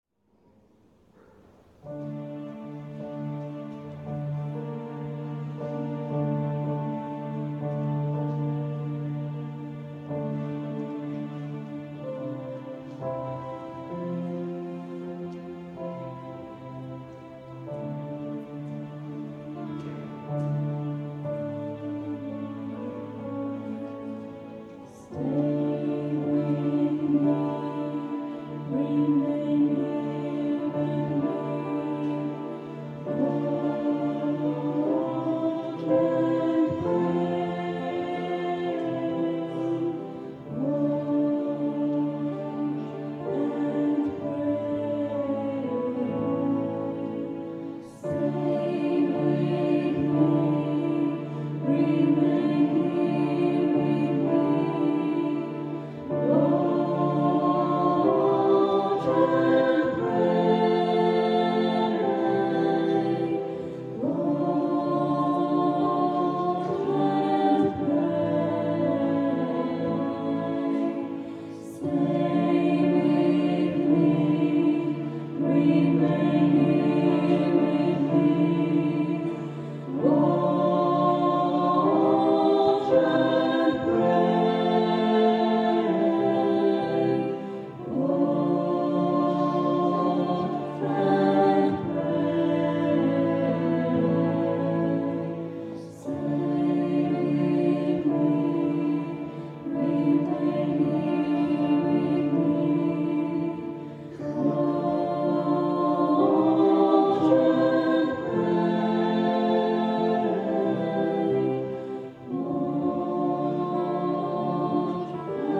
This beautiful Taiz� hymn was recorded Maundy Thursday Mass, 2005.